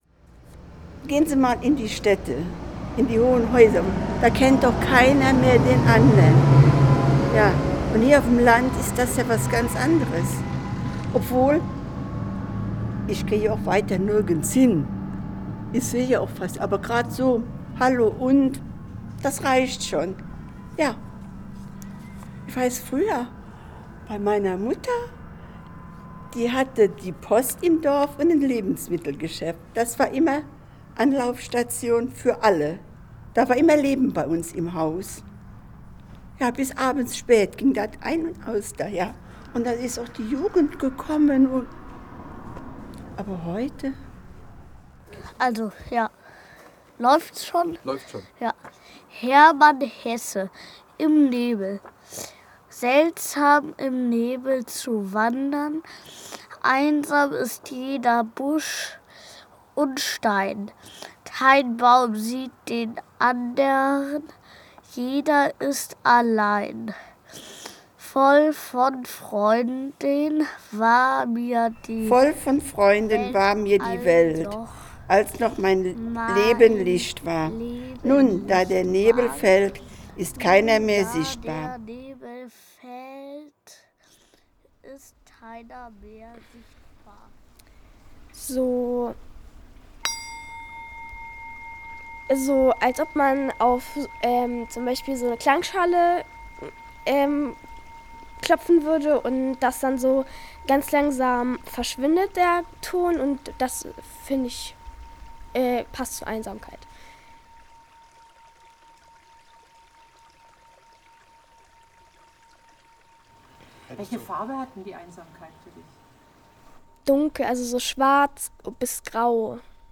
Audioworkshop mit Gedichten
mit Menschen aus der Region Perl, von 8 bis 93 Jahren
Aus den Tonaufnahmen der Gedichte und unserer Gespräche montierten wir zehn Kurzhörstücke.